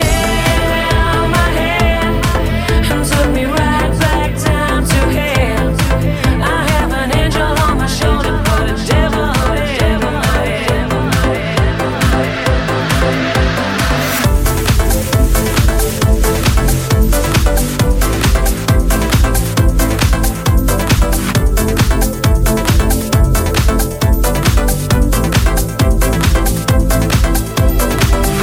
Genere: dance, house, electro, club, remix, 2008